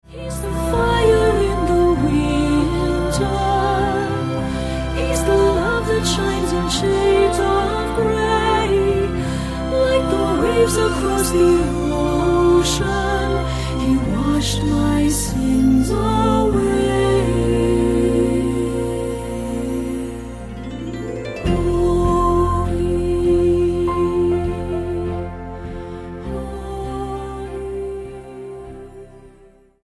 STYLE: Roots/Acoustic
melodious